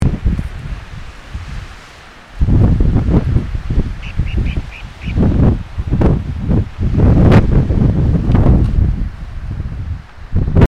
Calandrita (Stigmatura budytoides)
Nombre en inglés: Greater Wagtail-Tyrant
Localidad o área protegida: Área Natural Protegida El Doradillo
Condición: Silvestre
Certeza: Fotografiada, Vocalización Grabada
Calandrita.mp3